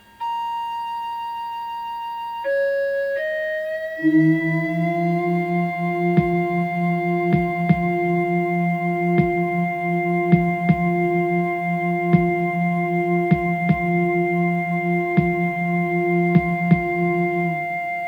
CD Audio Book (Spoken Word/Music)